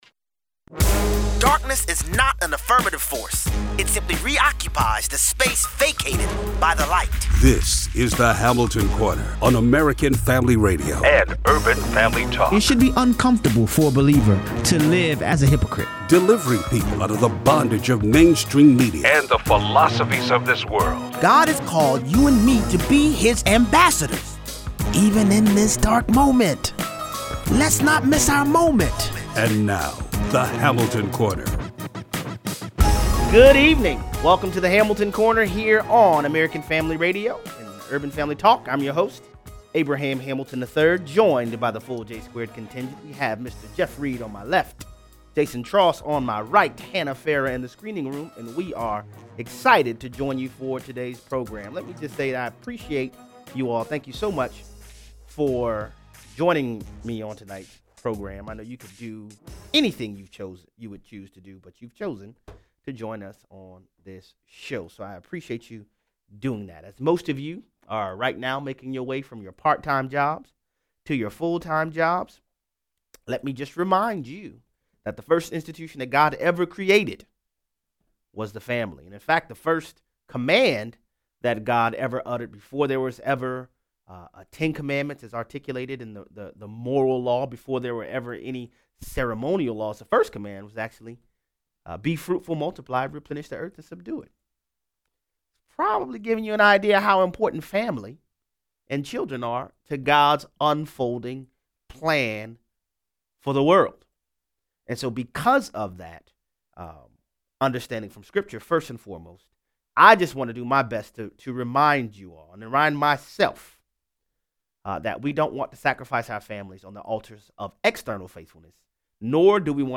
Congress offers a 1,700 page continuing resolution (CR) to fund the government that most Congress members couldn’t have read. It has an interesting amnesty/sanctuary provision in it. Callers weigh in.